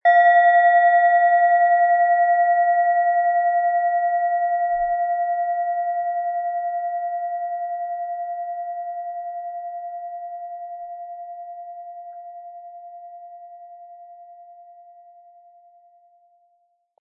Tibetische Bauch- und Kopf-Klangschale, Ø 11,6 cm, 180-260 Gramm, mit Klöppel
Durch die überlieferte Fertigung hat sie dafür diesen besonderen Spirit und eine Klangschwingung, die unser Innerstes berührt.